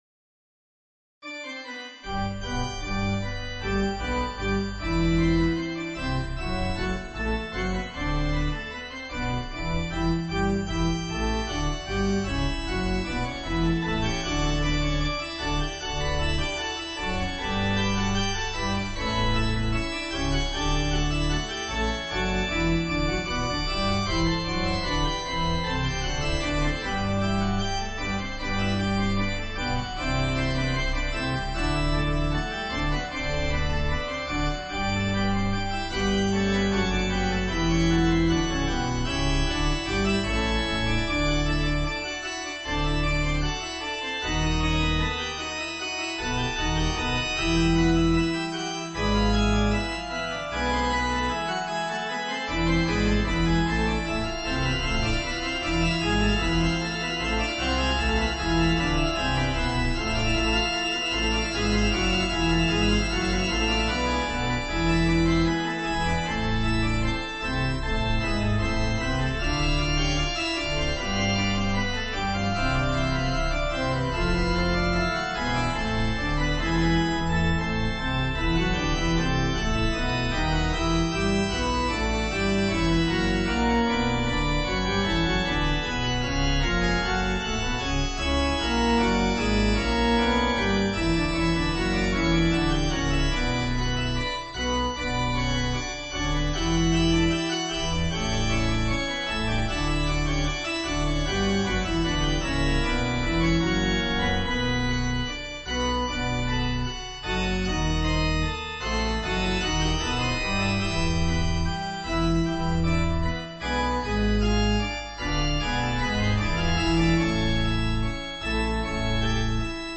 If you have a subwoofer, now would be a good time to make sure it's properly anchored to the floor.
This sample shows off Sfx's ability to render and mix SoundFont patches from within SAOL/Sfx. The organ patch is a SoundFont patch from the EMU 8MB GM Soundfont. The Reverb effect is written in SAOL.